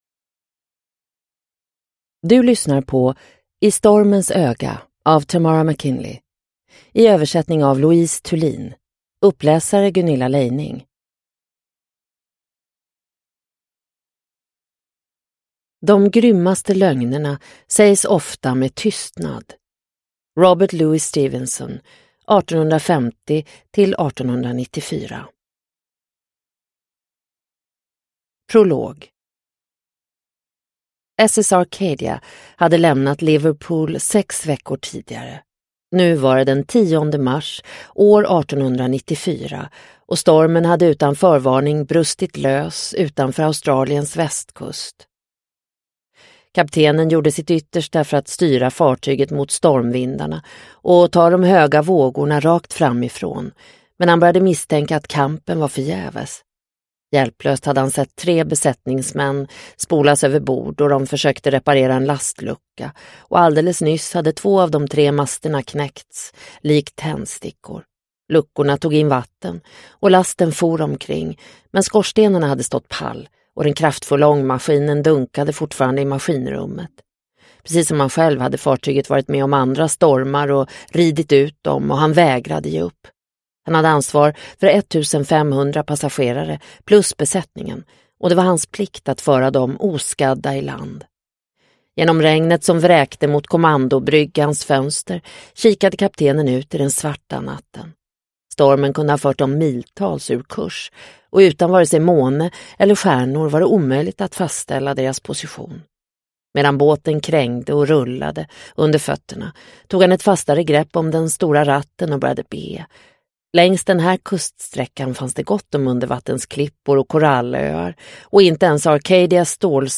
I stormens öga – Ljudbok – Laddas ner